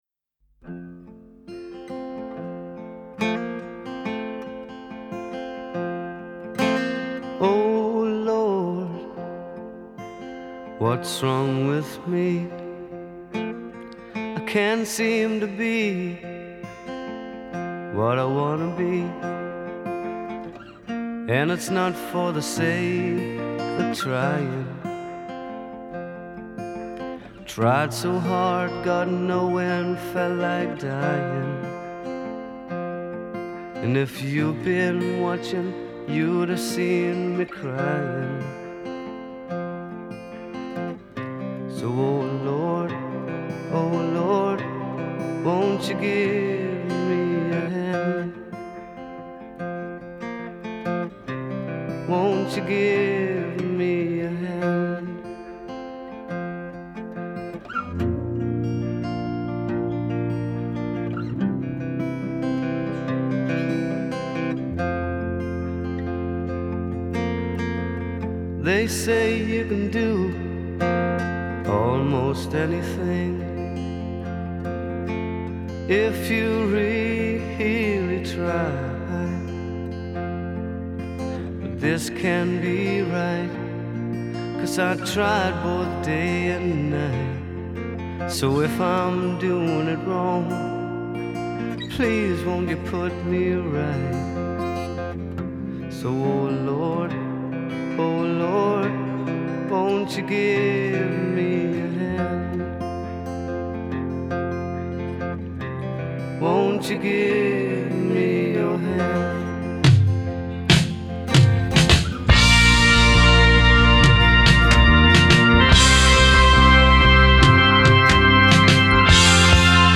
Genre: Rock, Glam Rock